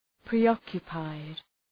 Προφορά
{prı’ɒkjə,paıd}